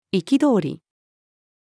憤り-anger-female.mp3